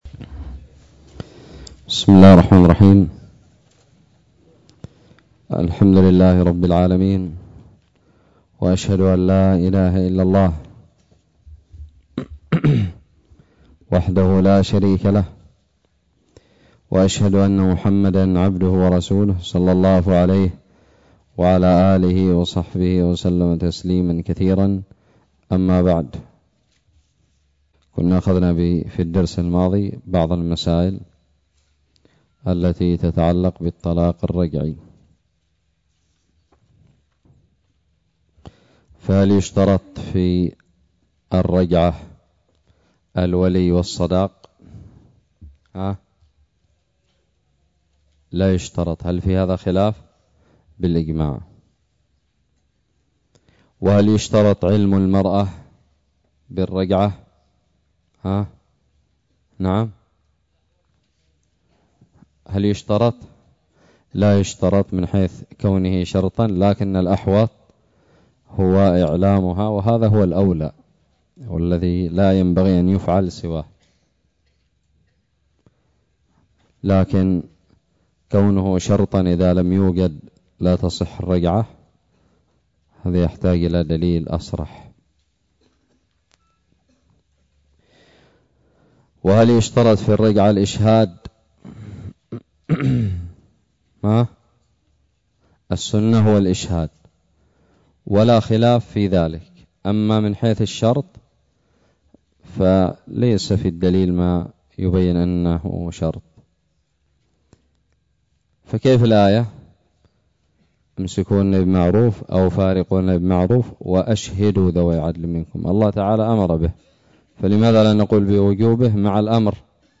ألقيت بدار الحديث السلفية للعلوم الشرعية بالضالع.